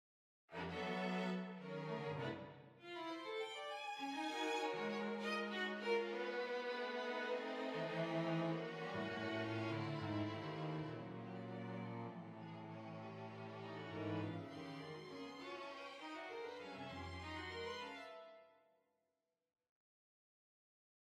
NP4 native sounds: